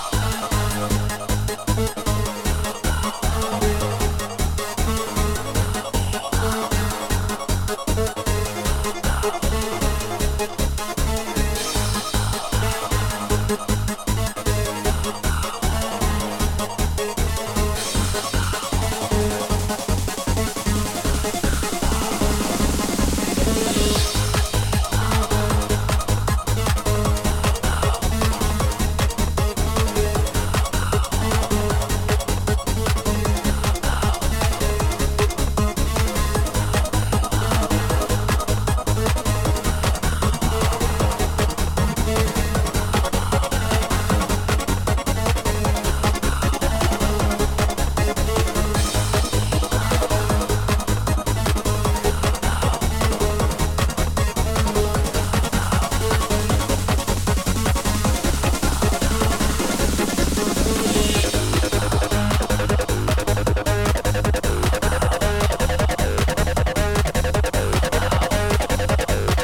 ハードだけど、親しみやすさが感じられるAcid！